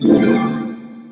hypergem_destroyed.wav